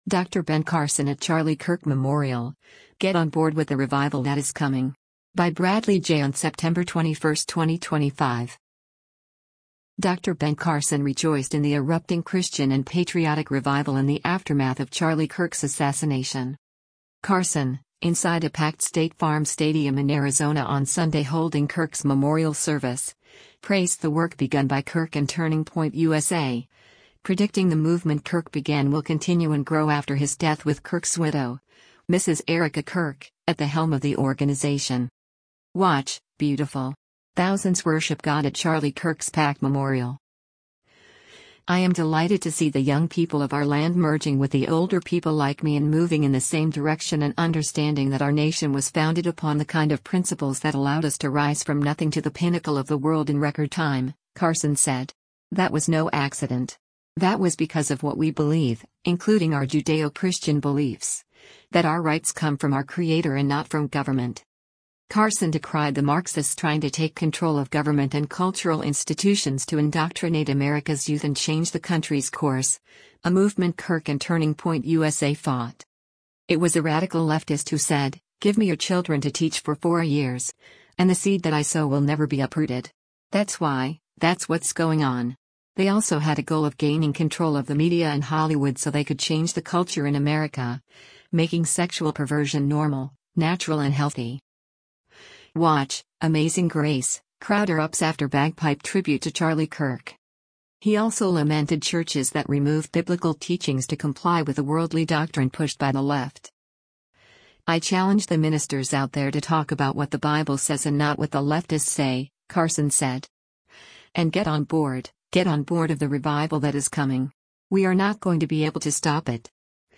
Carson, inside a packed State Farm Stadium in Arizona on Sunday holding Kirk’s memorial service, praised the work begun by Kirk and Turning Point USA, predicting the movement Kirk began will continue and grow after his death with Kirk’s widow, Mrs. Erika Kirk, at the helm of the organization.